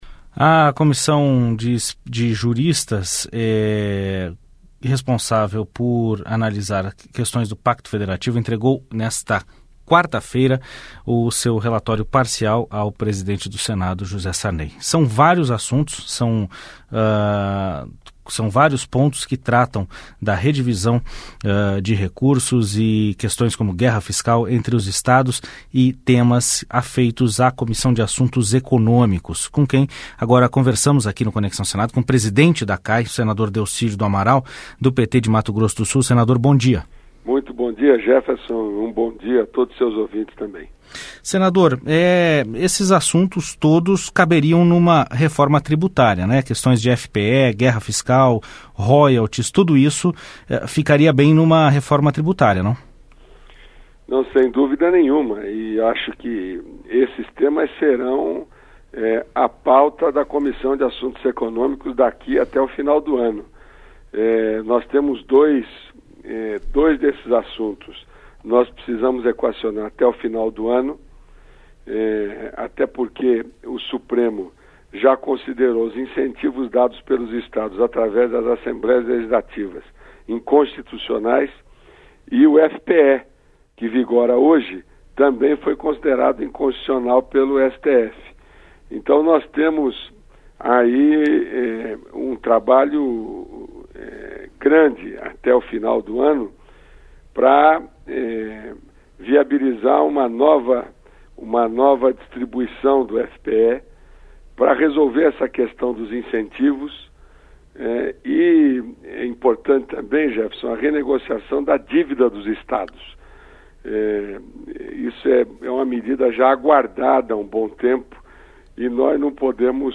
Entrevista com o presidente da Comissão de Assuntos Econômicos do Senado, senador Delcídio do Amaral (PT-MS).